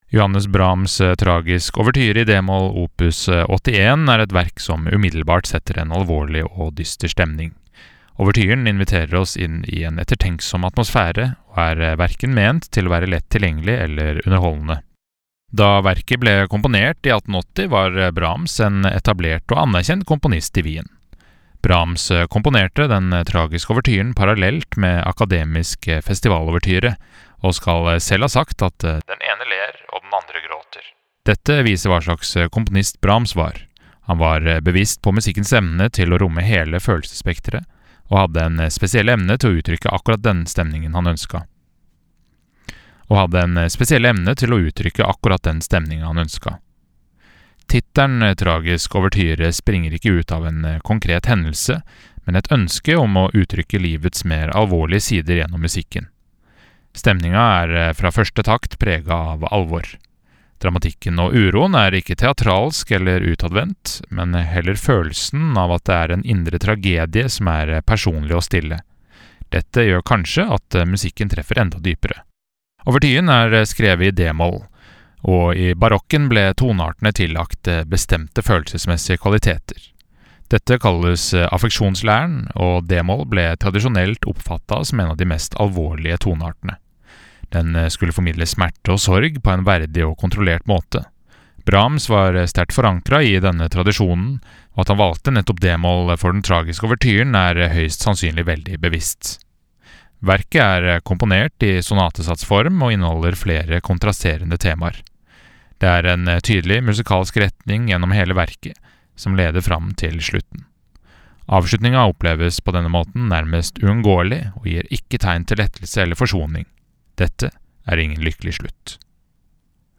VERKOMTALE-Johannes-Brahms-Tragisk-Ouverture.mp3